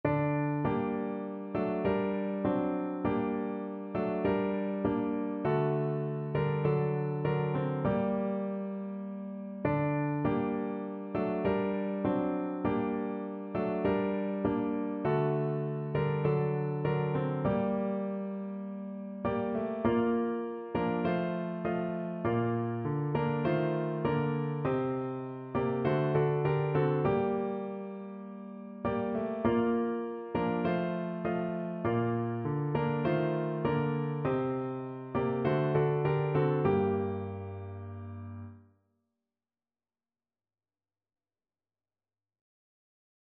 No parts available for this pieces as it is for solo piano.
4/4 (View more 4/4 Music)
Piano  (View more Intermediate Piano Music)
Christian (View more Christian Piano Music)
Armenian